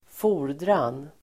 Uttal: [²f'o:r_dran]